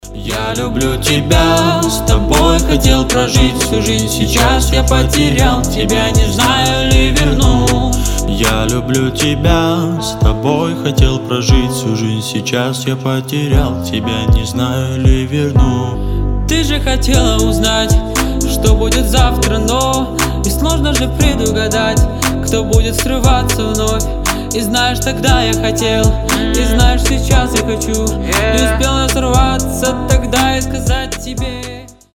• Качество: 320, Stereo
Хип-хоп
грустные